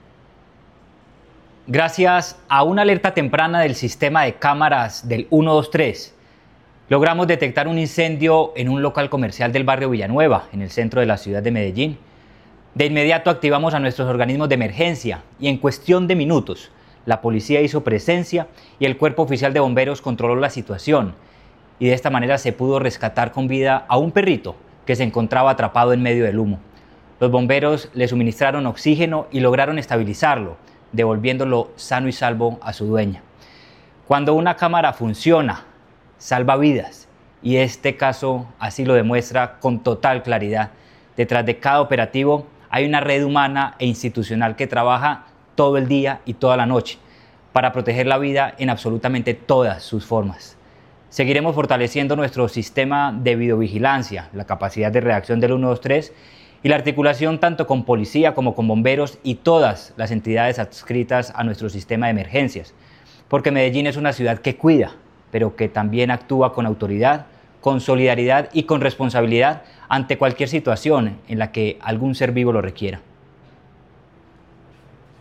Declaraciones-del-secretario-de-Seguridad-y-Convivencia-Manuel-Villa-Mejia.mp3